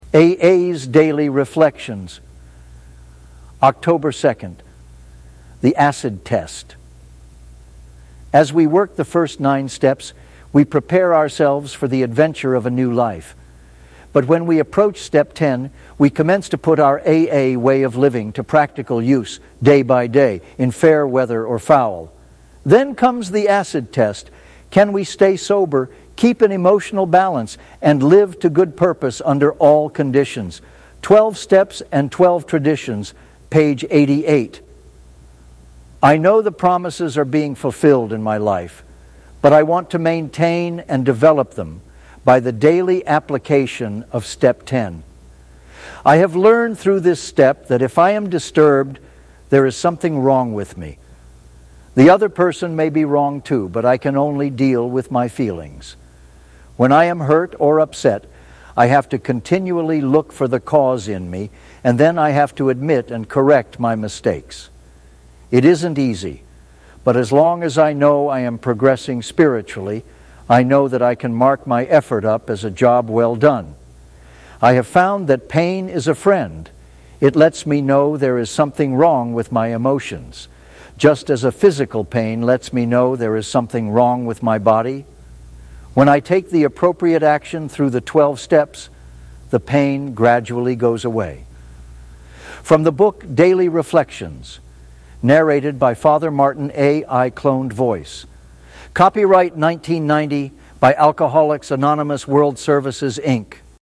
Cloned Voice.